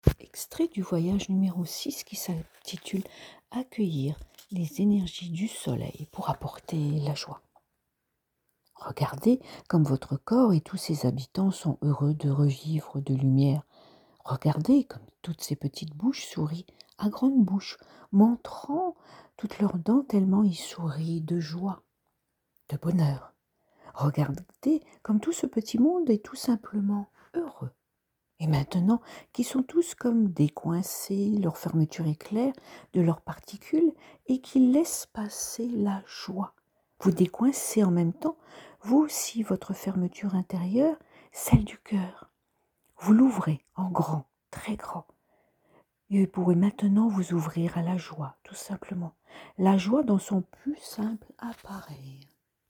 Méditations guidées : extrait à l'écoute pour faire votre choix